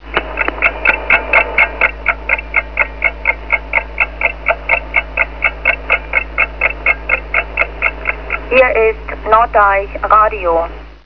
Recordings of Time Signal Stations